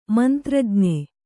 ♪ mantrajñe